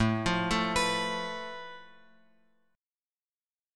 Asus2#5 chord {x x 7 4 6 5} chord